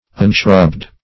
Unshrubbed \Un*shrubbed"\, a.
unshrubbed.mp3